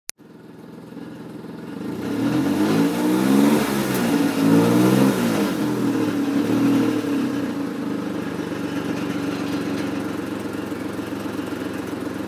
Aygo turns into a hotrod ... time to fix the exhaust ...